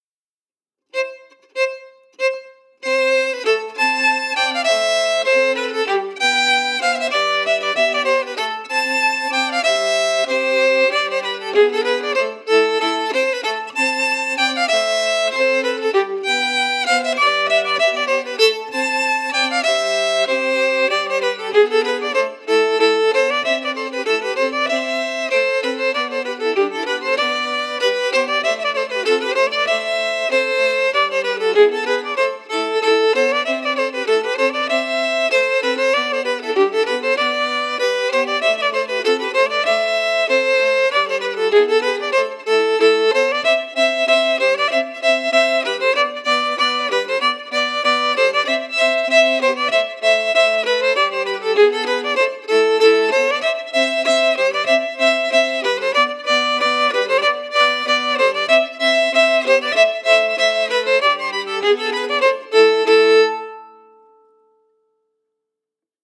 Key: Am
Form: Reel
Melody emphasis
Red-House-audio-melody-emphasized.mp3